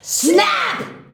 SSSSSSNAP.wav